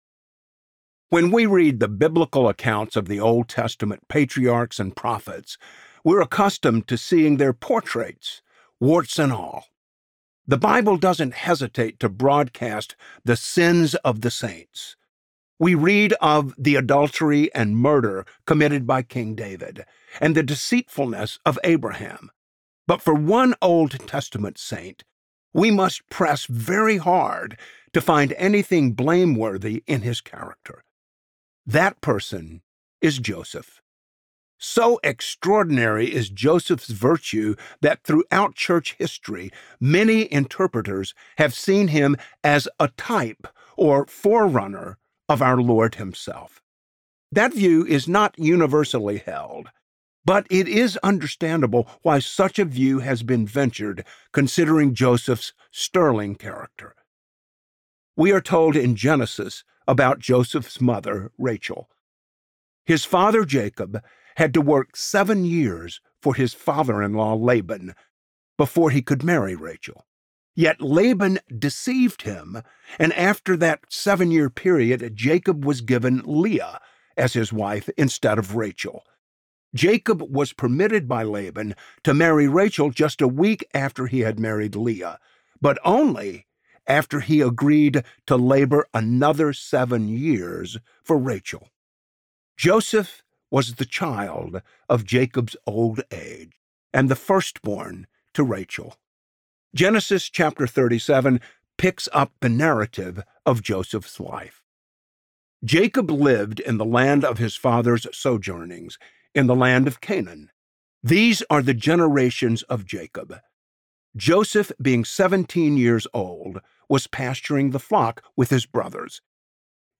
Joseph: From Dreamer to Deliverer: R.C. Sproul - Audiobook Download, Book | Ligonier Ministries Store